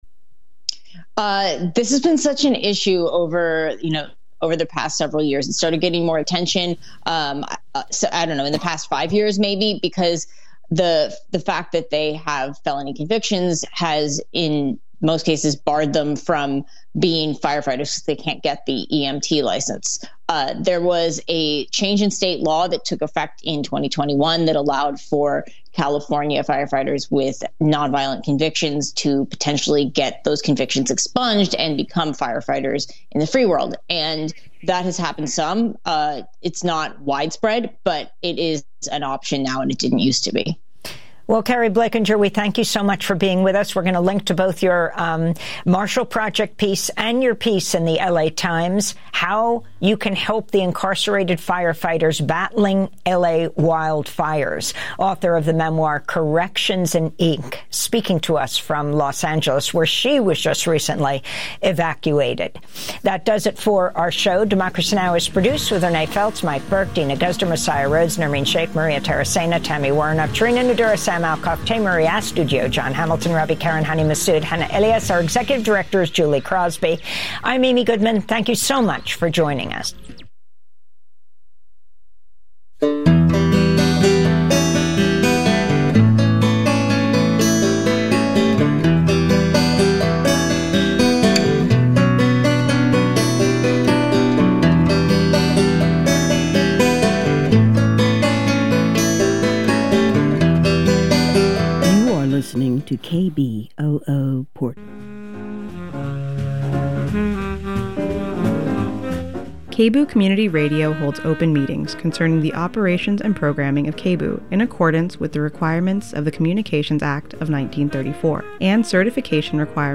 Non-corporate, community-powered, local, national and international news